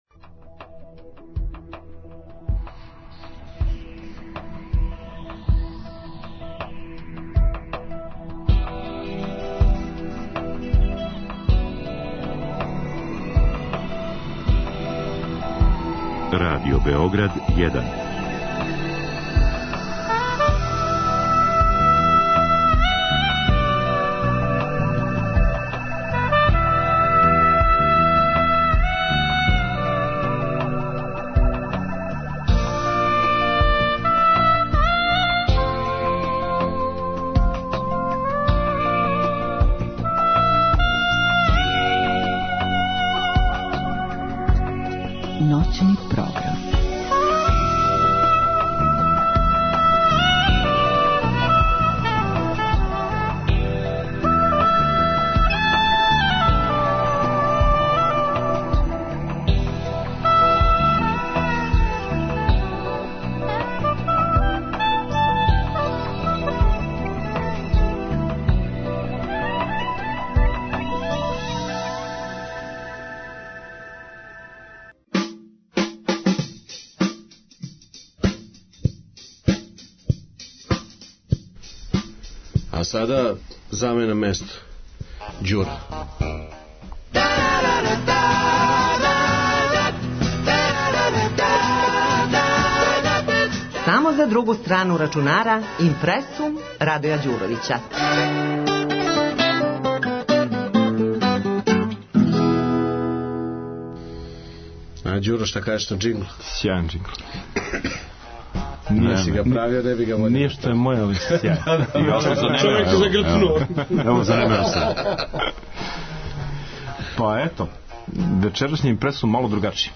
Temom "Biti ili ne biti?" bavio se i IMPRE§UM emisije "Druga strana računara" noćnog programa RadioBGD1 koji je emitovan 1.2.2014.godine.